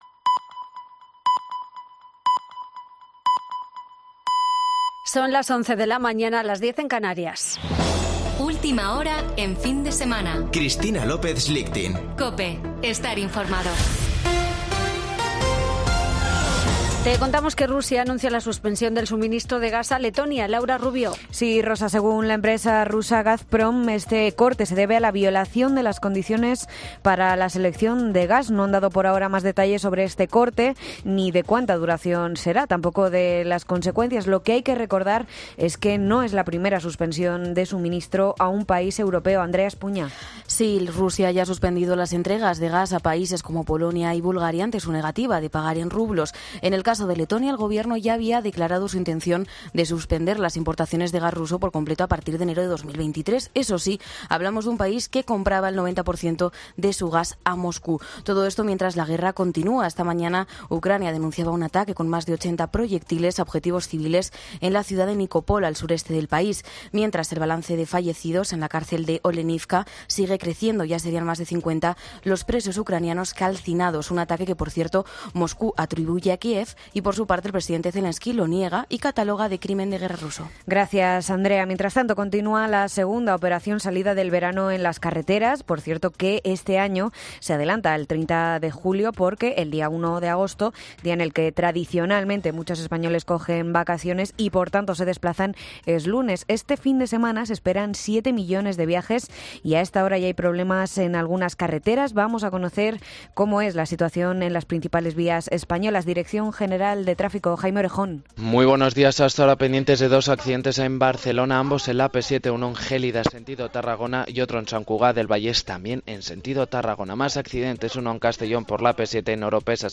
Boletín de noticias de COPE del 30 de julio de 2022 a las 11:00 horas